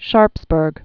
(shärpsbûrg)